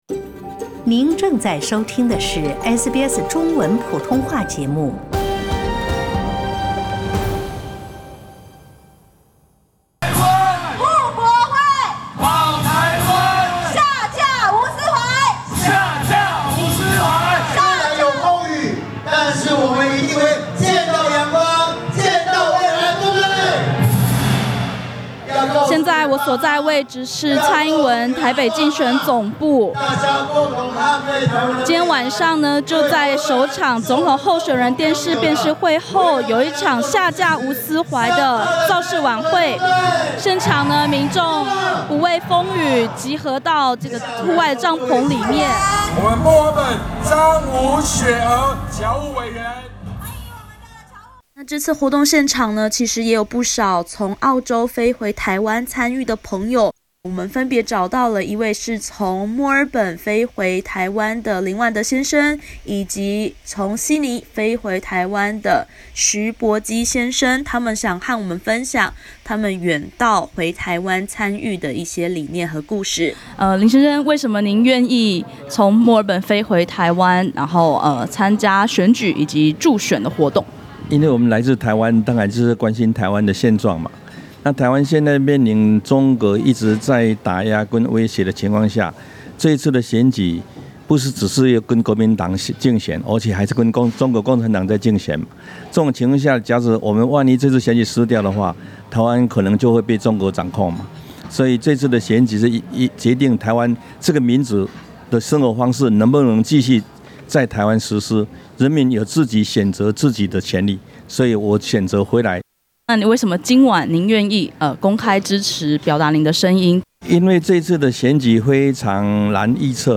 点击上方图片收听本台记者从台北发来的报道。